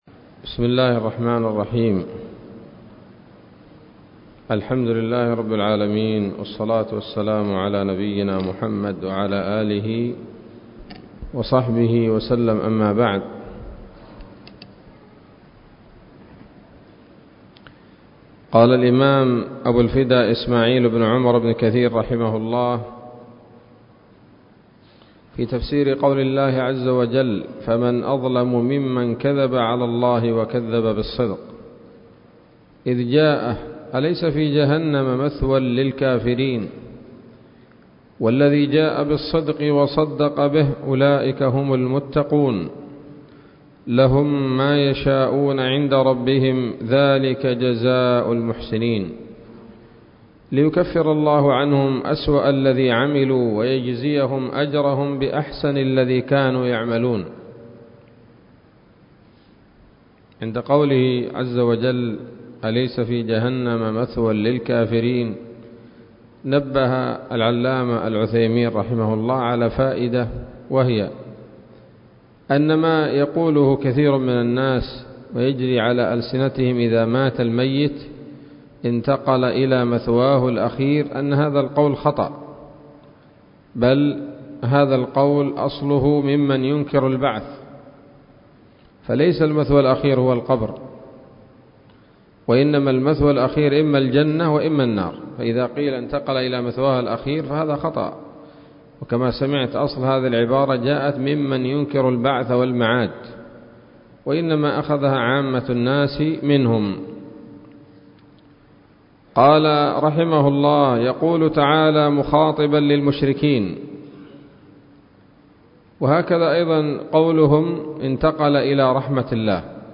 الدرس التاسع من سورة الزمر من تفسير ابن كثير رحمه الله تعالى